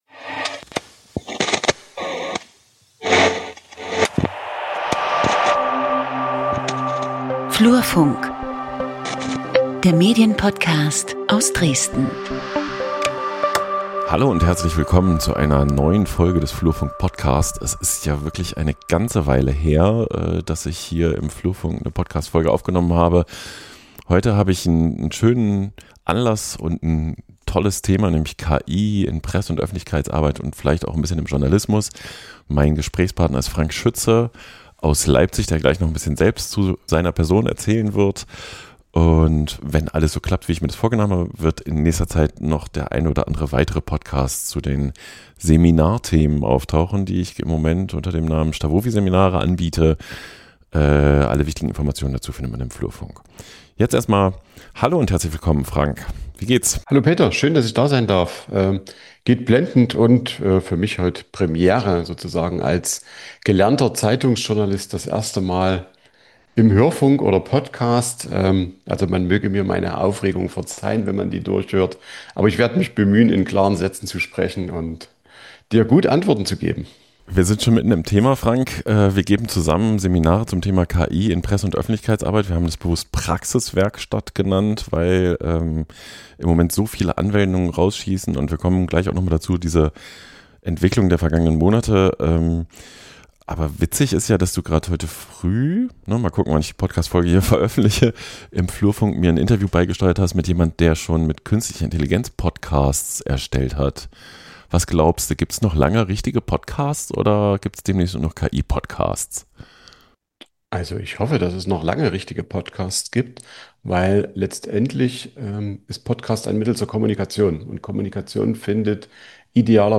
Im Gespräch diskutieren wir, wo KI-Tools wirklich Mehrwert bringen, wie ein praxiserprobter Workflow aussieht und an welchen Stellen Risiken und offene Fragen bleiben – von Datenschutz über Ethik bis hin zur Frage, wie stark KI die Arbeit der Medien insgesamt verändert.